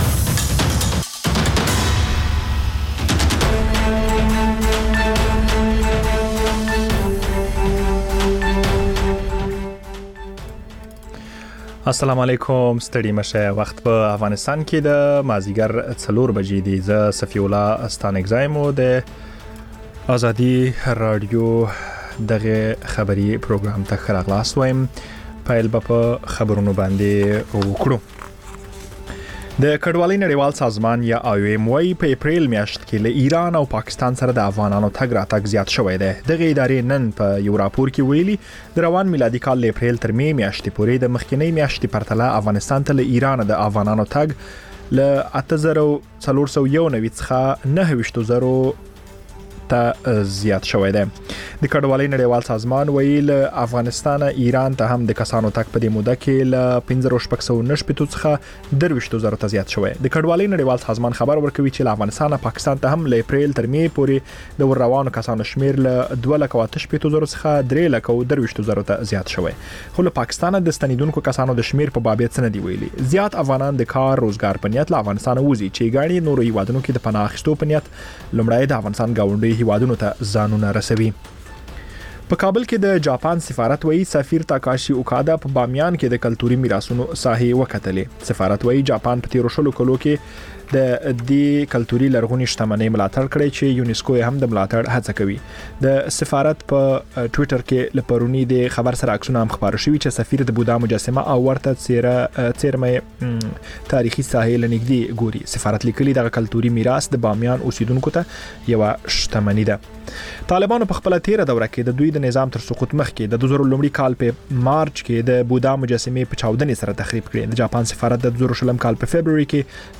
مازیګرنی خبري ساعت - P1 سټوډیو